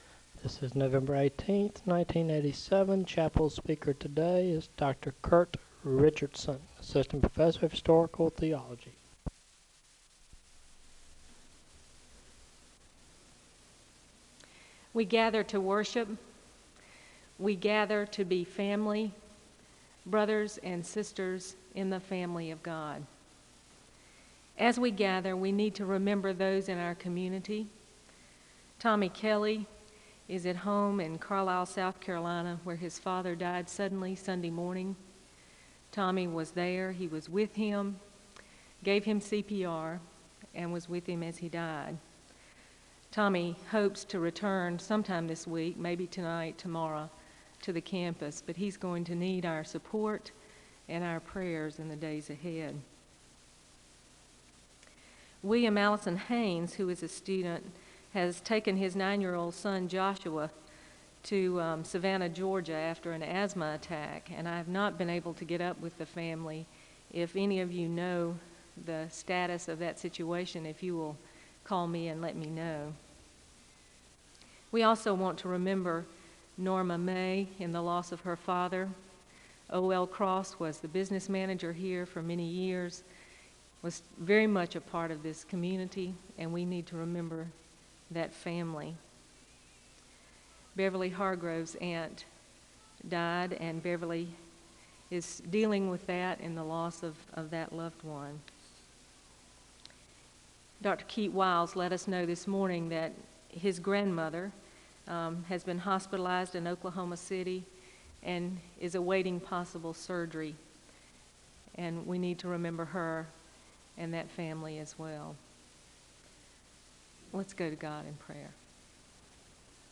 The service begins with prayer concerns and a moment of prayer (0:00-2:58). There is a Scripture reading from Psalm 116 and a moment of prayer (2:59-5:34).
The service concludes with a benediction (25:16-26:09).
Resource type Audio Citation Archives and Special Collections, Library at Southeastern, Southeastern Baptist Theological Seminary, Wake Forest, NC.